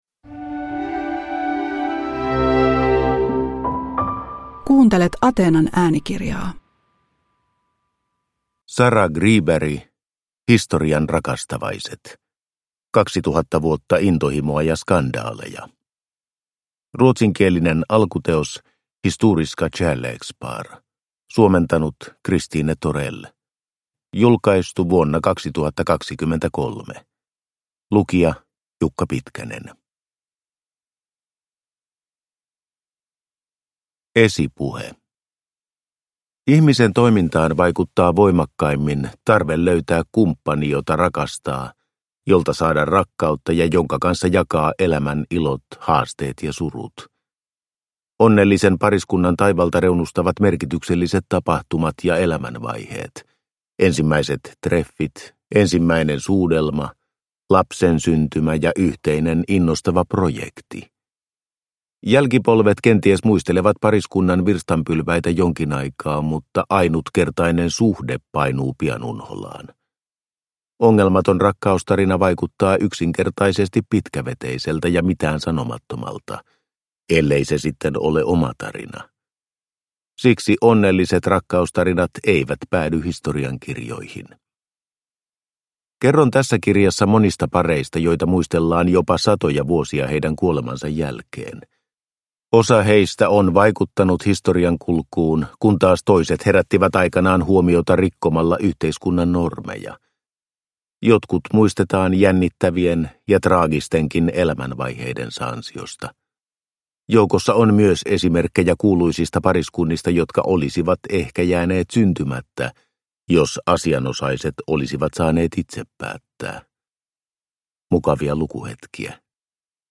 Historian rakastavaiset – Ljudbok – Laddas ner